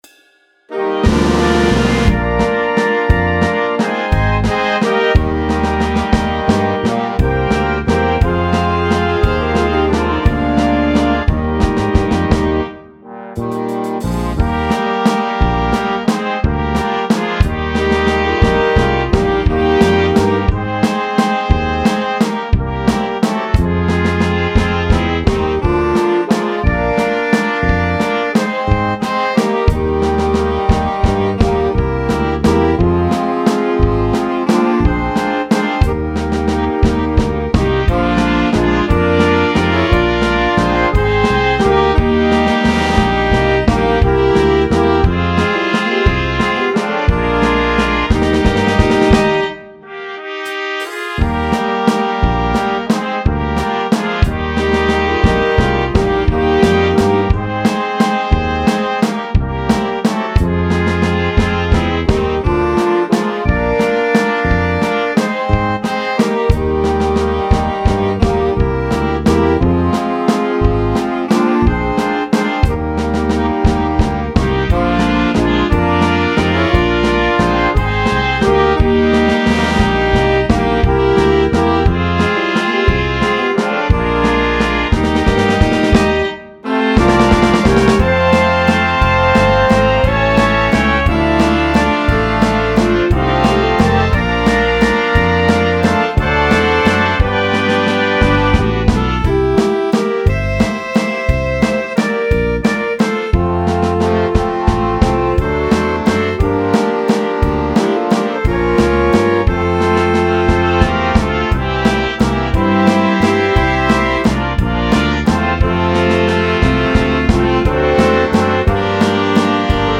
Dychová hudba Značky
Spievané valčíky Zdieľajte na